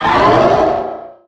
Cri de Méga-Lucario dans Pokémon HOME.
Cri_0448_Méga_HOME.ogg